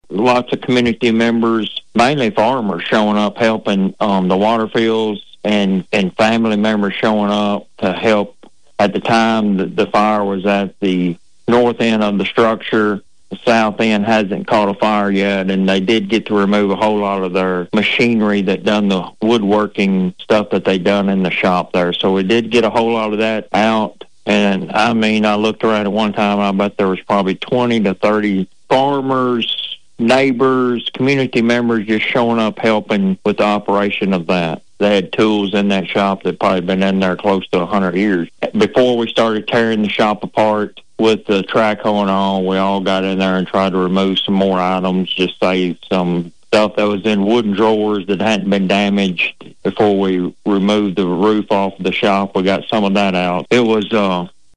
Police Chief Gary Eddings tells Thunderbolt News more….